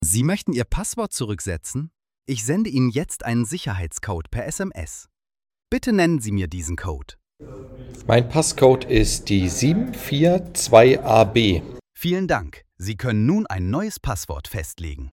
Exzellenter Kundenservice ist unsere Leidenschaft mit den natürlich menschlichen Stimmen unserer Sprachassistenten.
Sprachbeispiel: